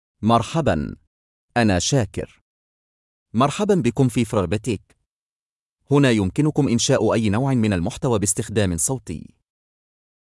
MaleArabic (Egypt)
Shakir is a male AI voice for Arabic (Egypt).
Voice sample
Shakir delivers clear pronunciation with authentic Egypt Arabic intonation, making your content sound professionally produced.